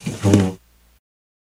Chair Scrape, Push Chair Back, Standing Up